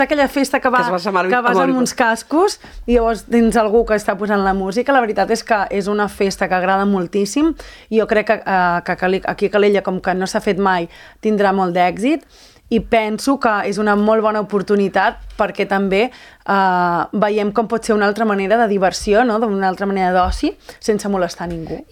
Ho ha confirmat la tinent d’Alcaldia de Promoció econòmica i Turisme, Cindy Rando, a l’entrevista política de Ràdio Calella TV.